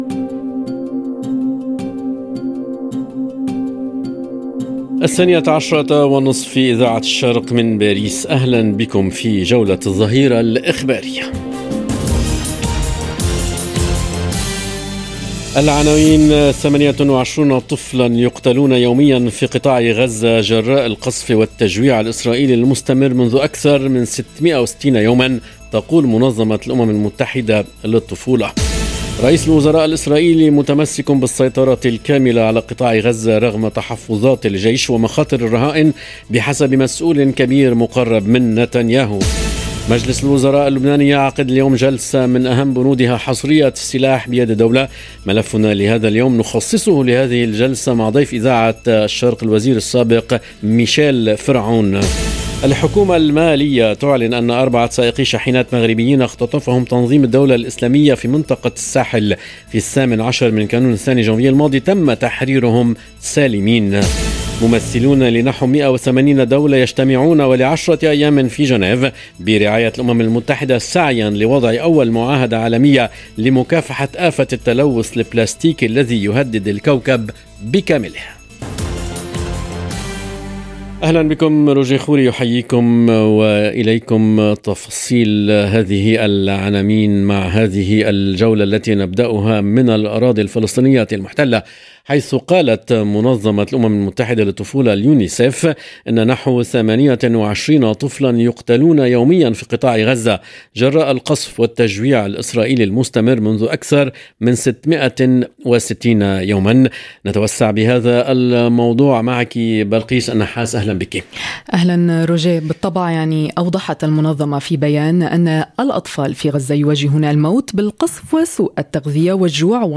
نشرة أخبار الظهيرة: وفقاً ليونيسف 28 طفلا يقتلون يوميا في قطاع غزة، جراء القصف والتجويع الإسرائيلي المستمر منذ أكثر من 660 - Radio ORIENT، إذاعة الشرق من باريس